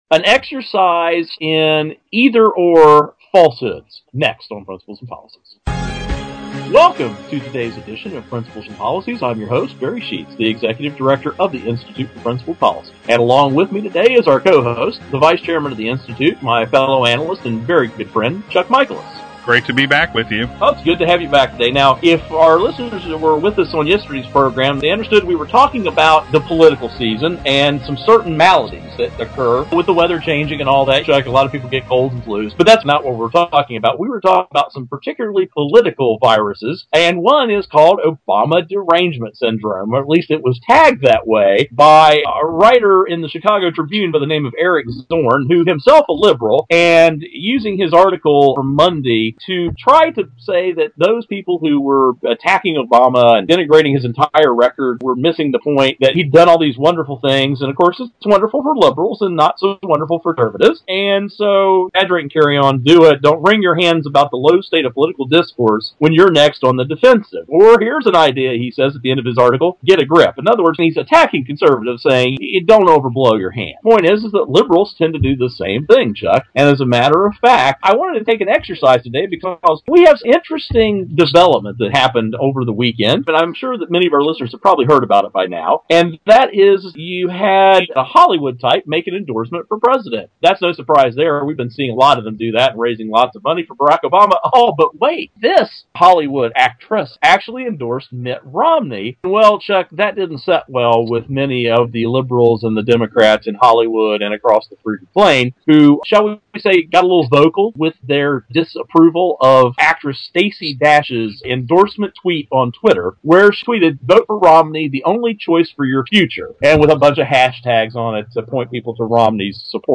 Our Principles and Policies radio show for Wednesday October 10, 2012.